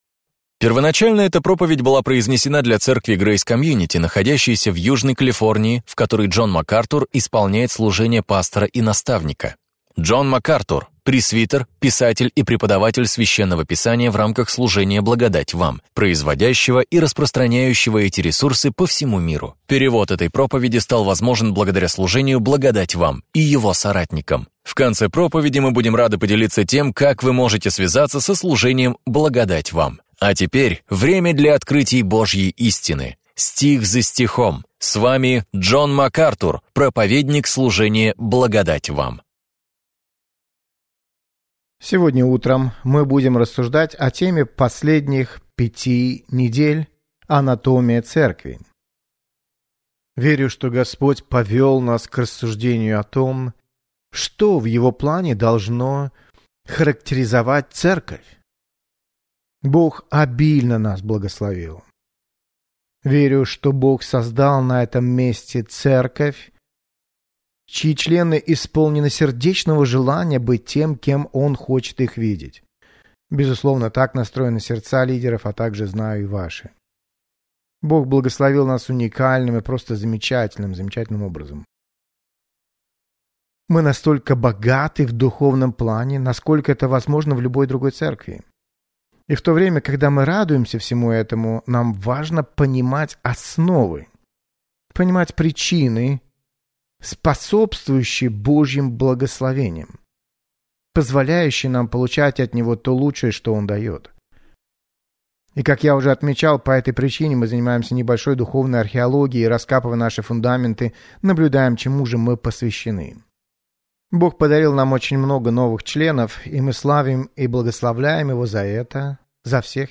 «Анатомия Церкви» – это ценная проповедь, поясняющая, как вы и ваша церковь можете прославлять Бога!